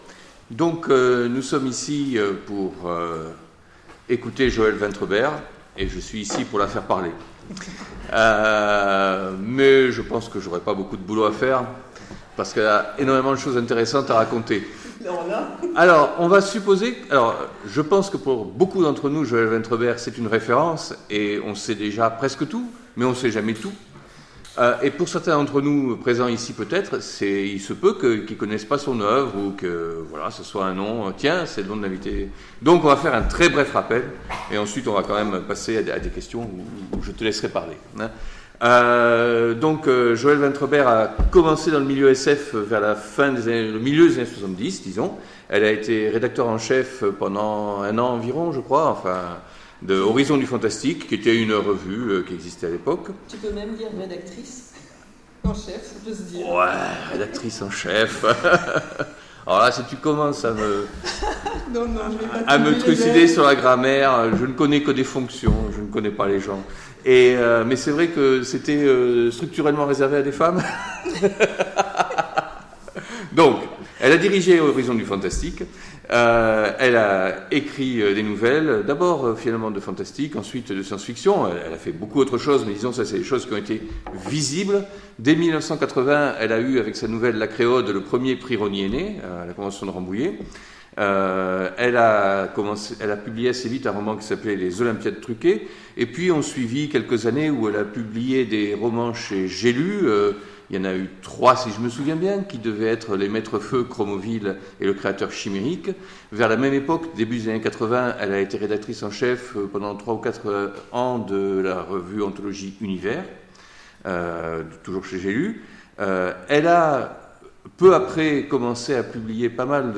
Mots-clés Rencontre avec un auteur Conférence Partager cet article